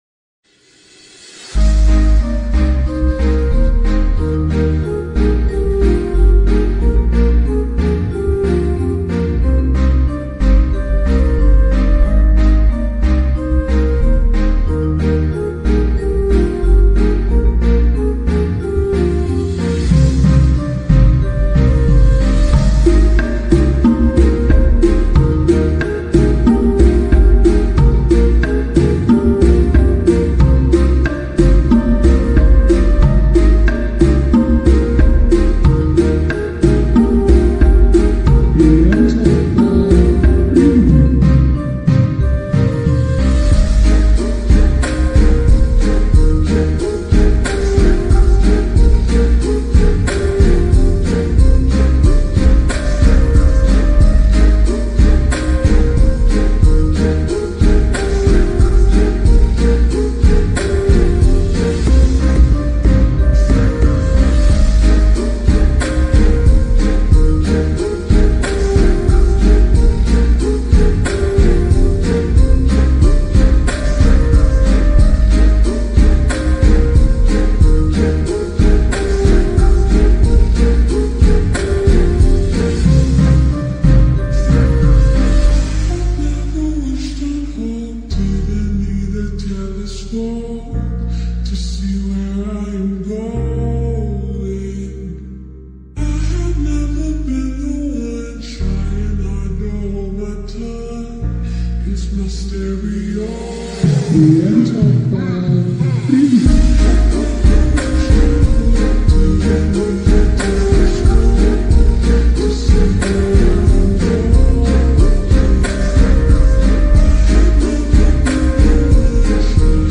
Slow Reverb